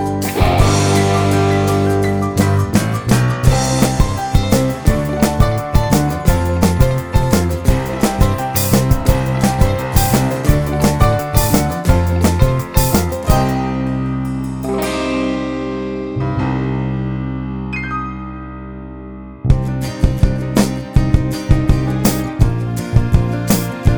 no Backing Vocals Soundtracks 2:29 Buy £1.50